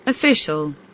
official kelimesinin anlamı, resimli anlatımı ve sesli okunuşu